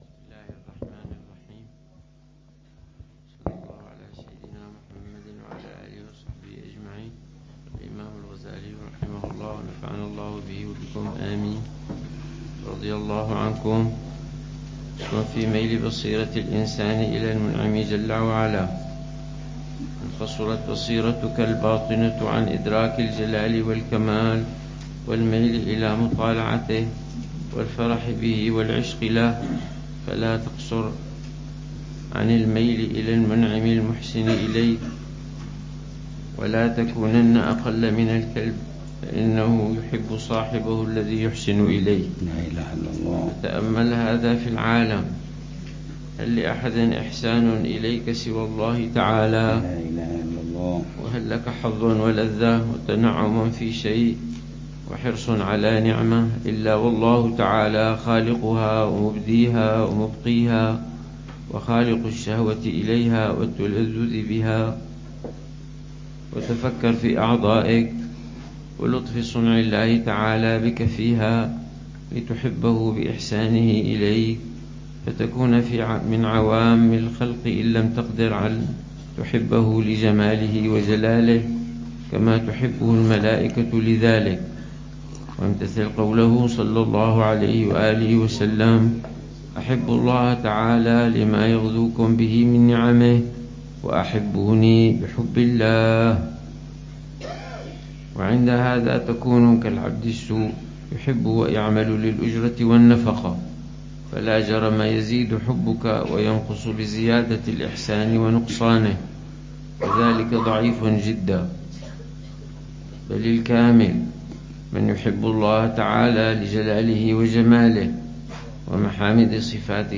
الدرس الأربعون للعلامة الحبيب عمر بن محمد بن حفيظ في شرح كتاب: الأربعين في أصول الدين، للإمام الغزالي .